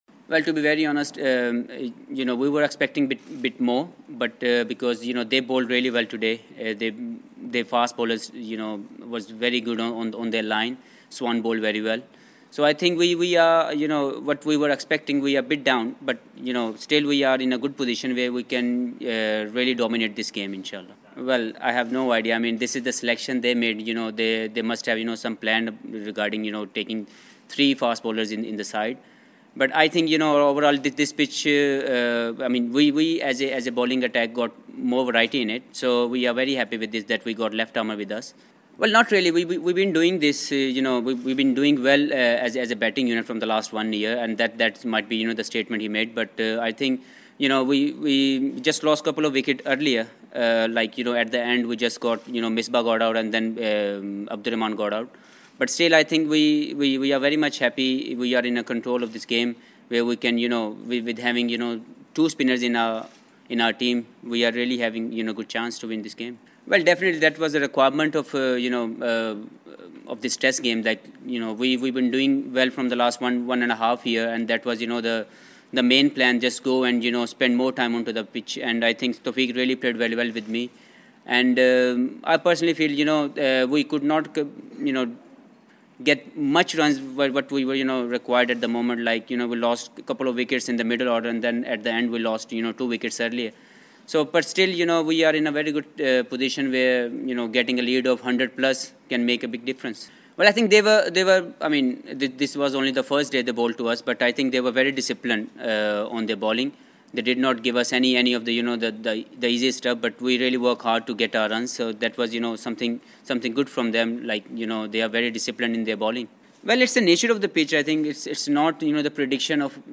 Pakistan all-rounder Mohammed Hafeez media conference (English), 18 January